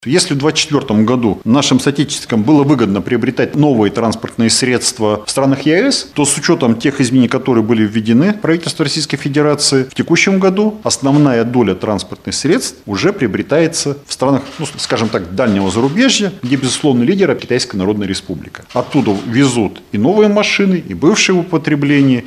По данным Уральского таможенного управления, с начала года было оформлено 1700 авто, а за весь 2024 — всего 500. Об этом рассказал начальник управления Алексей Фролов на пресс-конференции ТАСС-Урал.